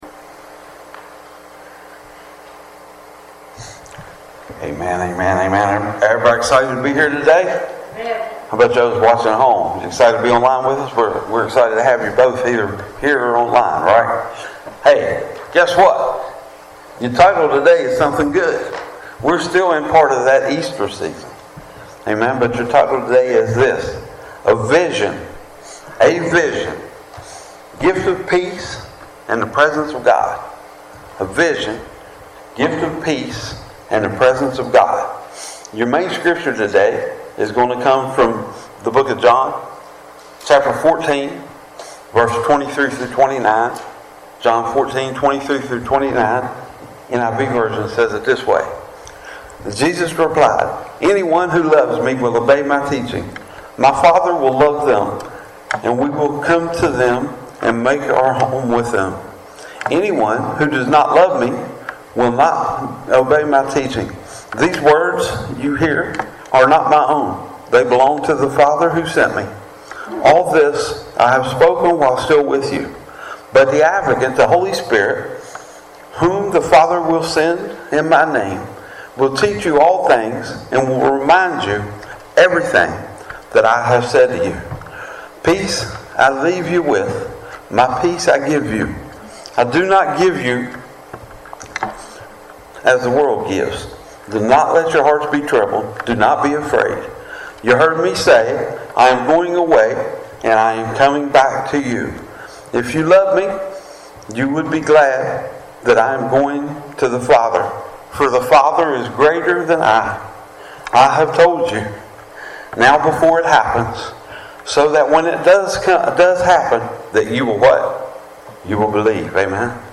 Sermons | Three Mile Wesleyan Church